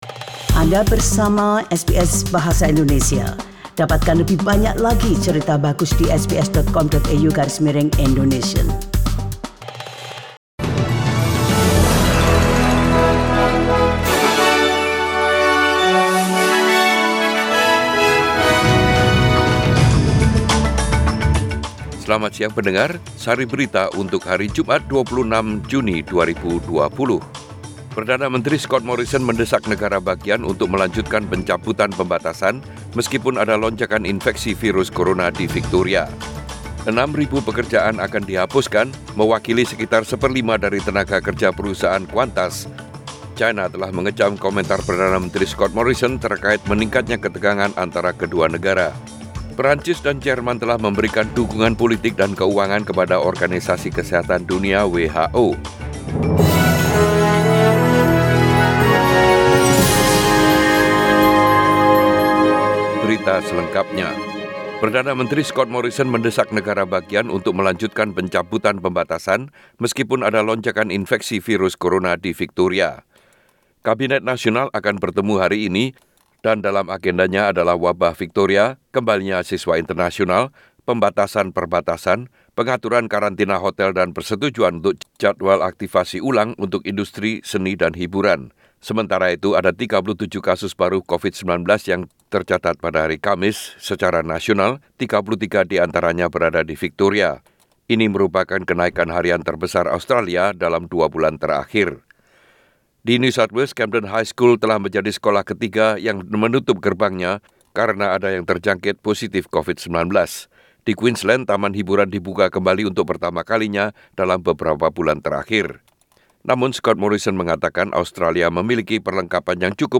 Warta Berita Radio SBS Program Bahasa Indonesia - 26 Juni 2020